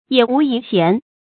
野無遺賢 注音： ㄧㄜˇ ㄨˊ ㄧˊ ㄒㄧㄢˊ 讀音讀法： 意思解釋： 民間沒有被棄置不用的人才。